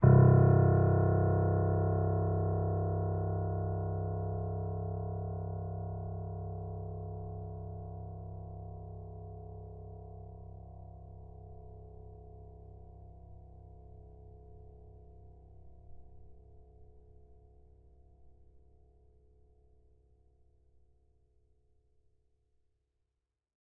sampler example using salamander grand piano
C1.ogg